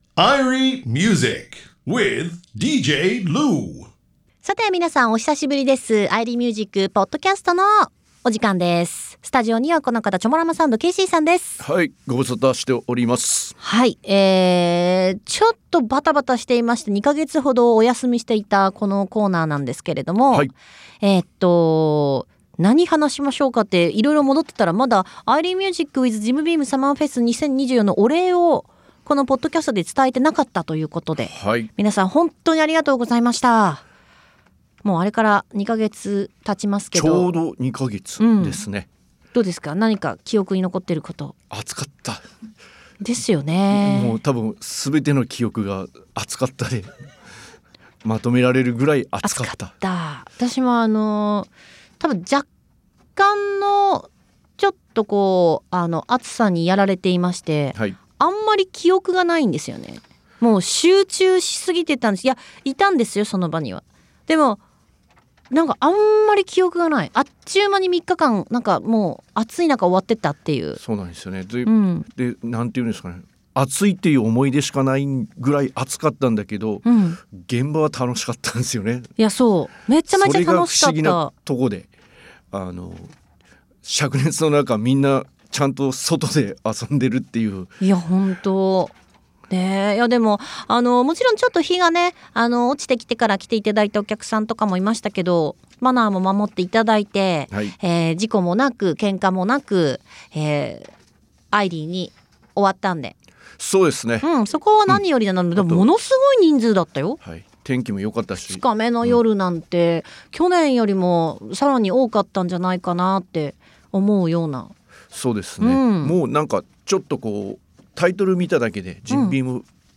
ゲストインタビューやこぼれ話をお届けしていきますよー！